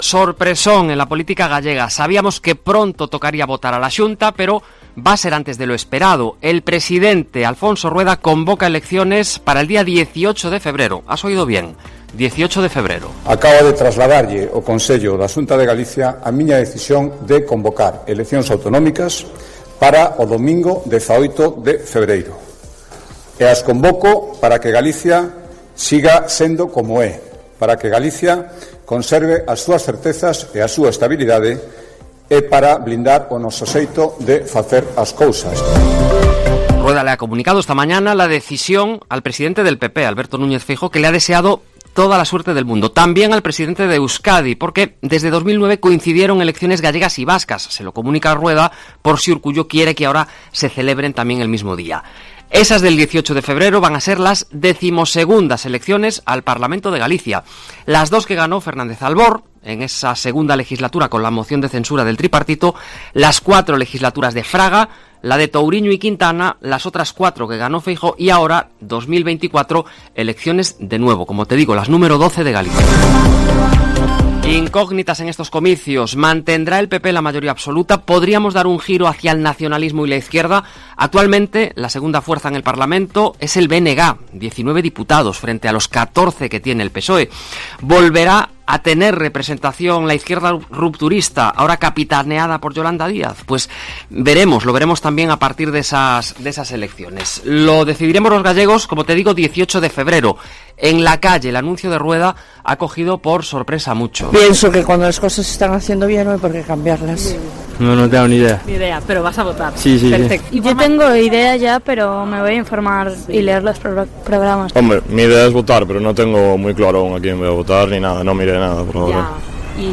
En la calle, la ciudadanía se enteraba casi con la pregunta de COPE, muchos aún no conocen ni a los candidatos: “Yo siempre voto al mismo por convicción personal”, nos explicaba una señora.